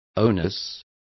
Complete with pronunciation of the translation of onus.